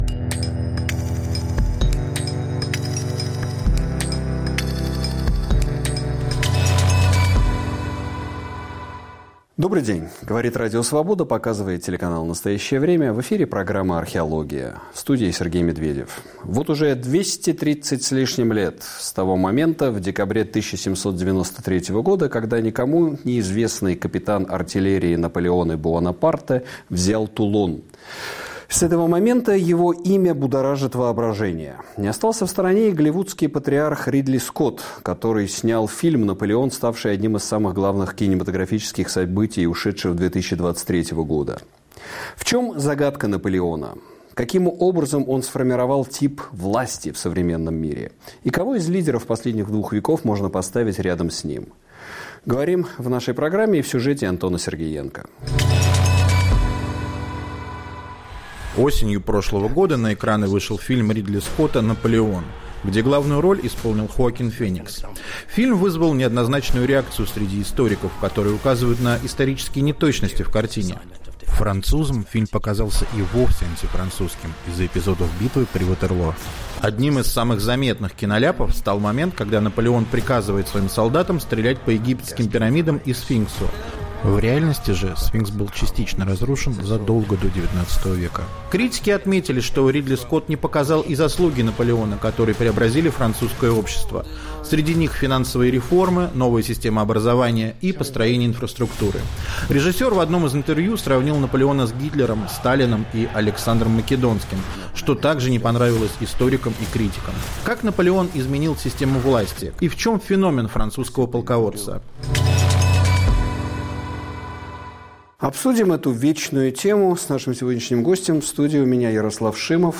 Повтор эфира от 17 января 2024 года.